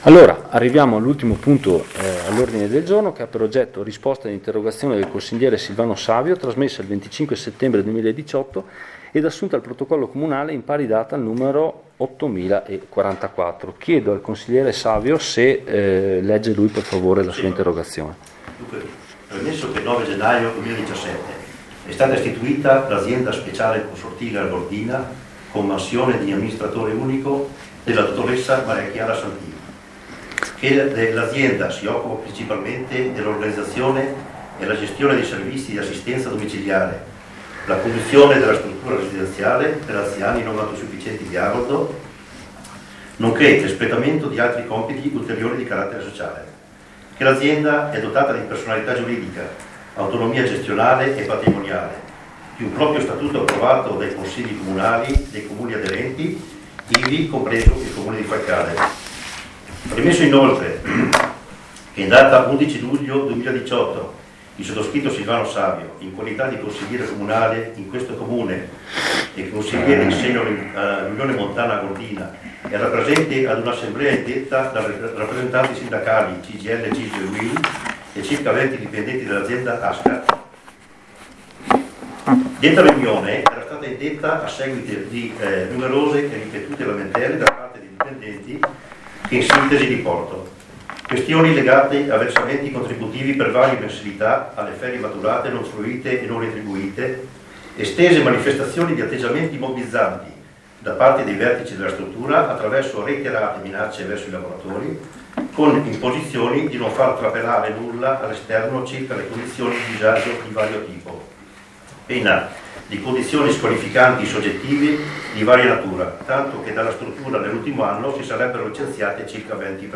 L’INTERO DIBATTITO CONSIGLIARE SUL PUNTO
DIBATTITO-COMPLETO.mp3